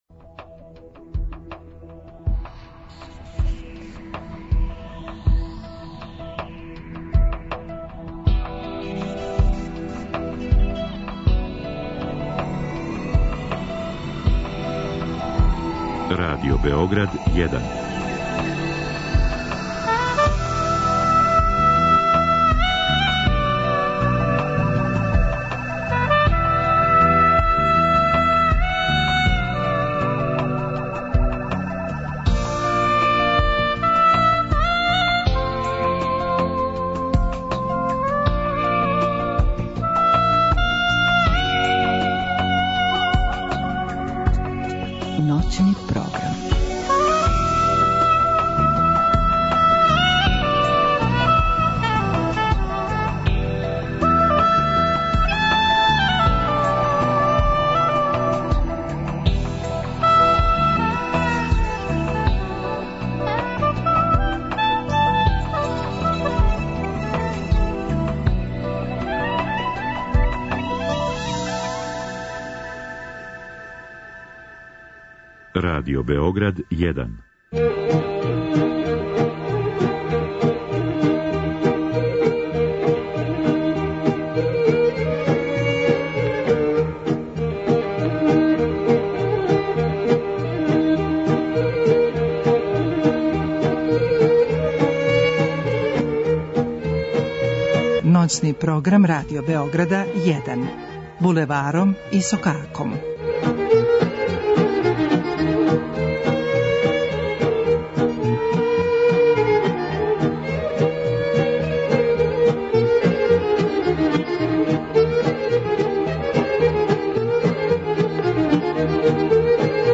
... и Нова година поново, сада јулијанска или по некима стара, а по некима српска. Ми смо вам припремили обиље добре музике, па ако је славите, верујемо да ће мо допринети вашем добром расположењу.